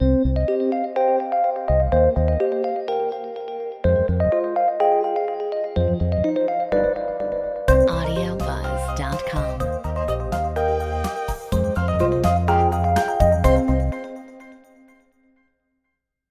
Metronome 125 BPM
Audio Logos Corporate Games Technology